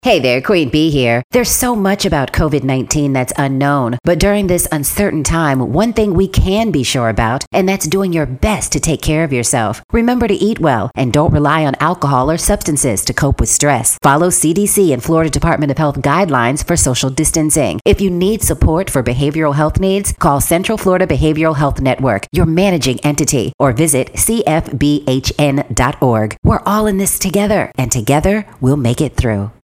COVID-19 Radio Spots